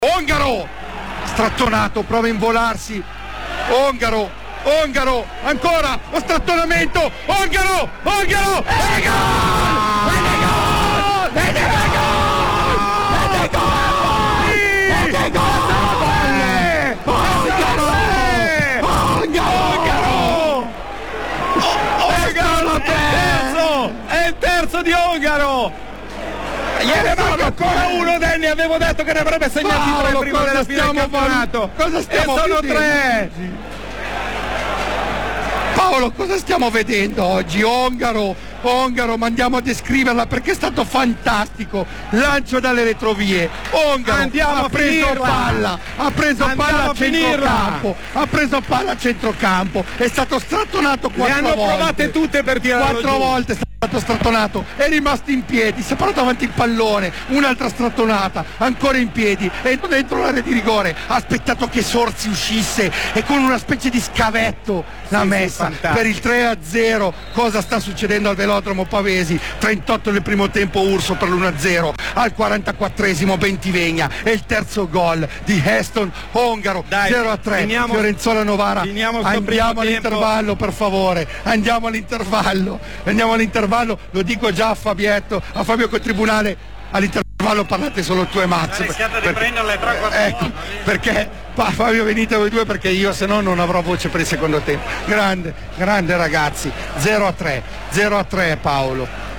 Rivivi con noi l'emozione dei tre gol azzurri della gara Fiorenzuola-Novara, direttamente dalla radiocronaca di Radio Azzurra: